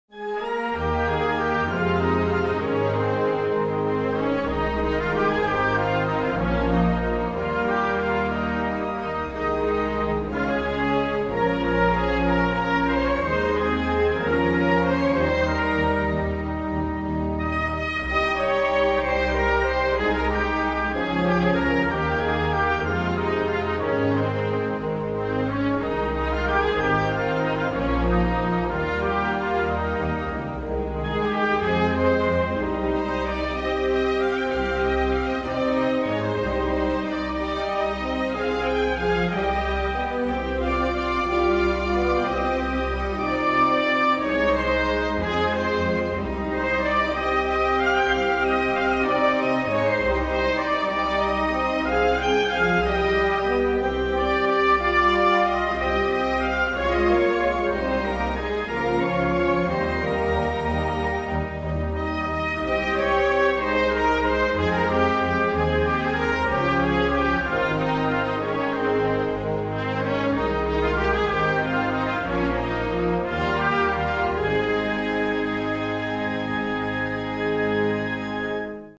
инструментальная версия